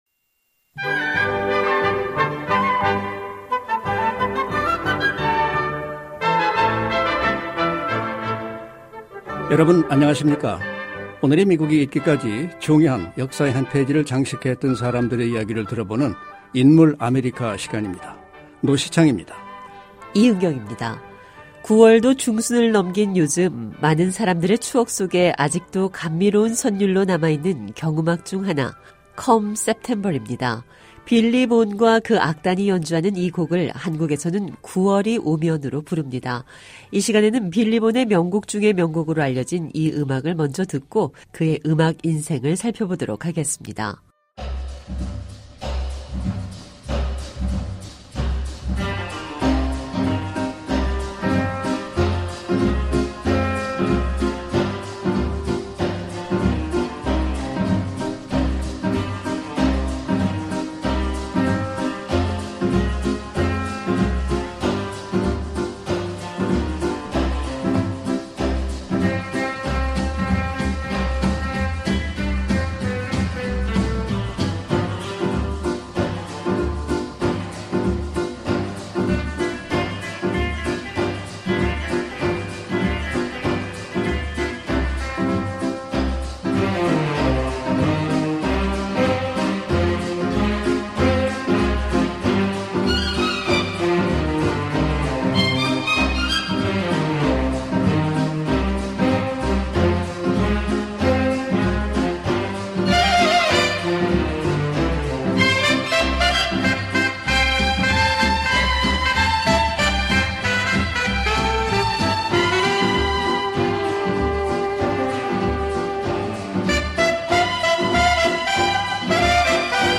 9월도 중순을 넘긴 요즈음, 많은 사람들의 추억 속에 아직도 감미로운 선률로 남아 있는 경음악 중 하나.